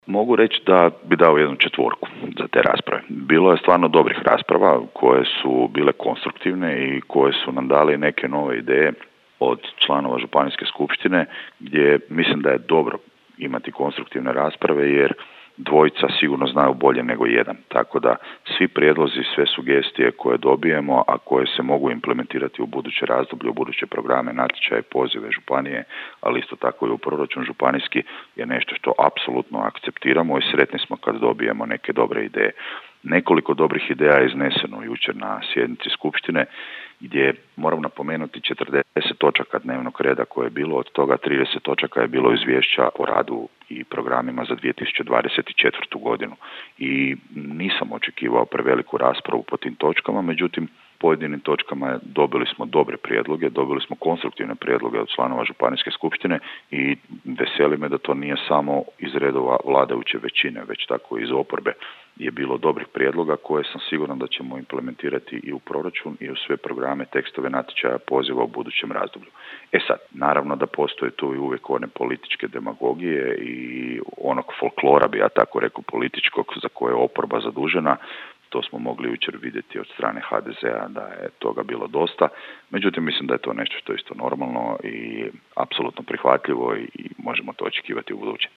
Županijski skupštinari okupili su na trećoj sjednici Županijske skupštine Koprivničko-križevačke županije u aktualnom sazivu i najviše raspravljali o rebalansu proračuna, što je detaljnije u Aktualnostima iz županije u programu Podravskog radija, komentirao župan Tomislav Golubić.